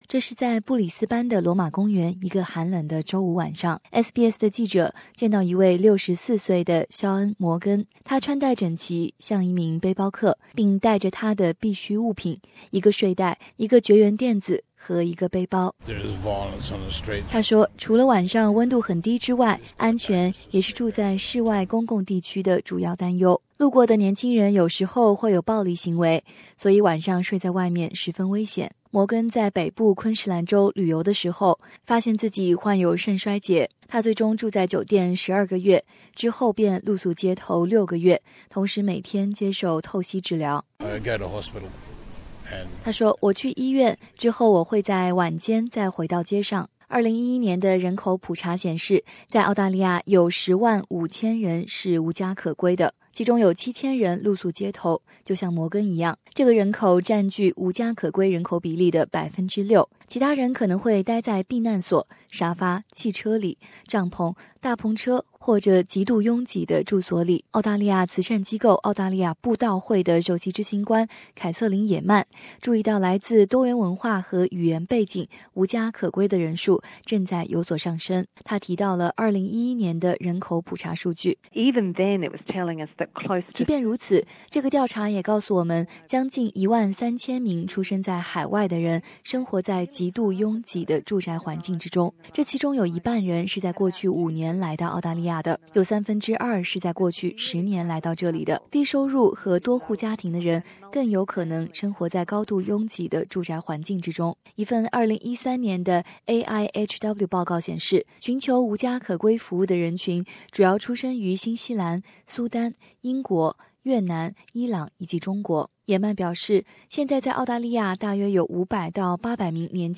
本周是无家可归意识周，SBS将带你去布里斯班的内城区公园看看，无家可归者的生活到底是什么样的。